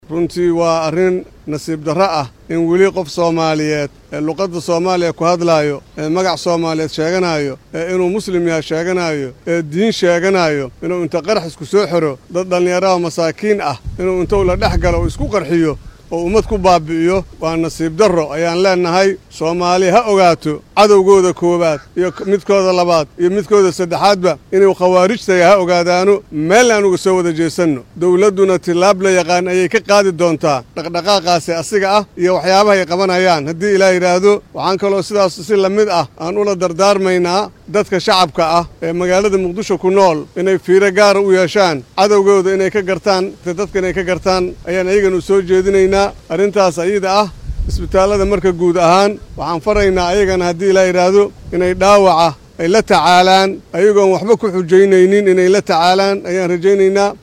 Guddoomiyaha gobolka Banaadir ahna duqa magaalada Muqdisho Yuusuf Xuseen Jimcaale (Madaale) ayaa sheegay in dowladdu ay tallaabo degdeg ah qaadi doonto oo ay uga jawaabeyso wixii dhacay. Shacabka ku nool magaalada Muqdisho ayuu ugu baaqay in ay cadowgooda ka dhex gartaan dadka. Waxaa uu nasiib darro ku tilmaamay in qof diin sheeganayo uu isku dhex qarxiyo shacab muslimiin ah.
Duqa-Muqdisho.mp3